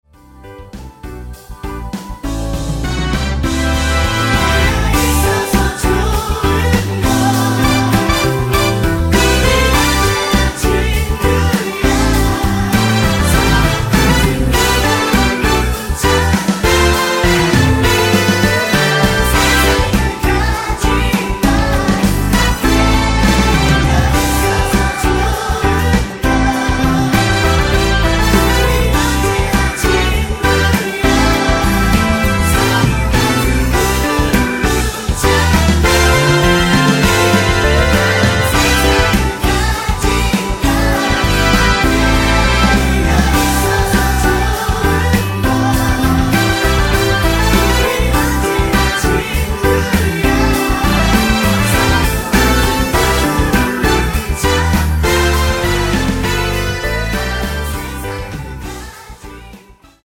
원곡 3분 지나서 부터 나오는 코러스 포함된 MR입니다.(미리듣기 확인)
원키에서(+2)올린 코러스 포함된 MR입니다.
앞부분30초, 뒷부분30초씩 편집해서 올려 드리고 있습니다.
중간에 음이 끈어지고 다시 나오는 이유는